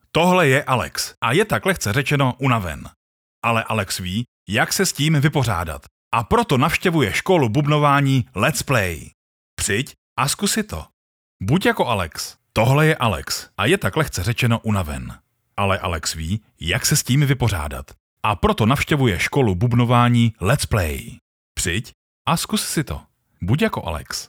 Součástí každého jobu je i základní postprodukce, tedy odstranění nádechů, filtrování nežádoucích frekvencí a ekvalizace a nastavení exportu minimálně 96kHz/24bit, okolo -6dB, jestli se nedohodneme jinak.
Mužský voiceover - hlas do krátkých reklamních spotů!